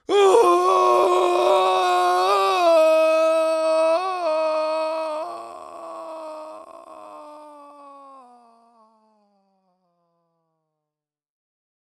human
Cartoon Male Screams While Falling 2